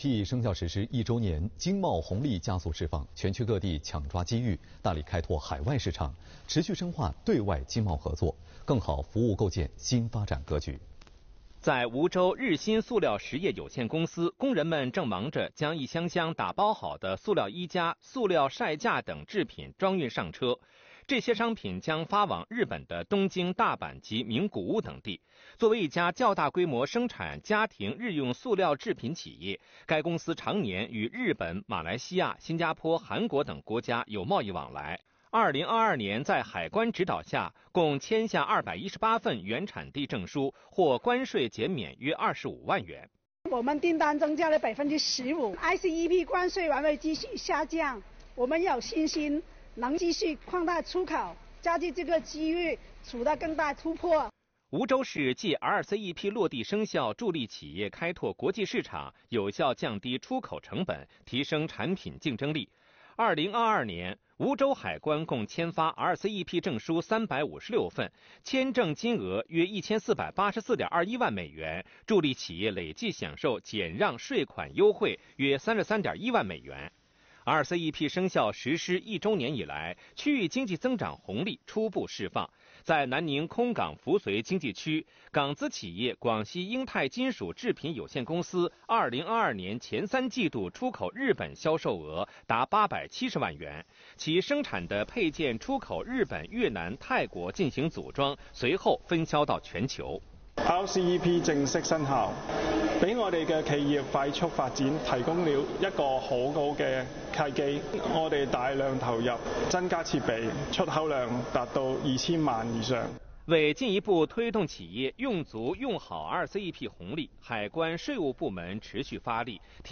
来源：广西广播电视台新闻频道